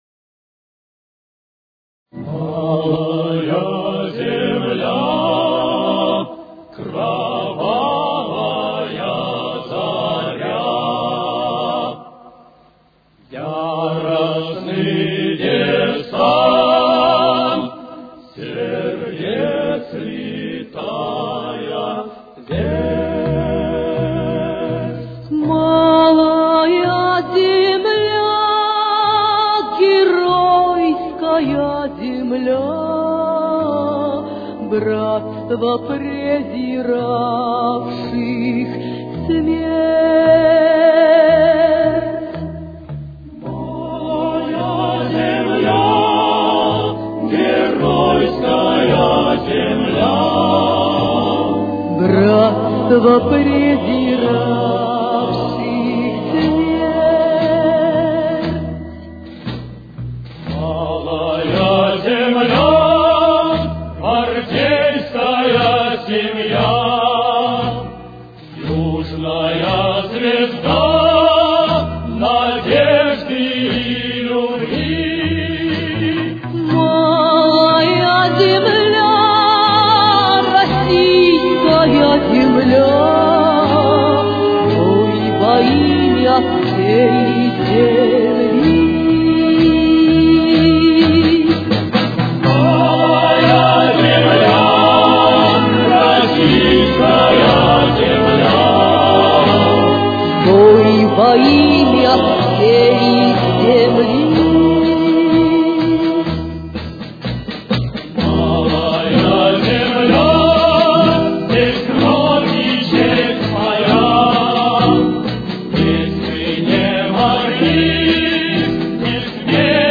с очень низким качеством (16 – 32 кБит/с)
Тональность: Ми-бемоль минор. Темп: 61.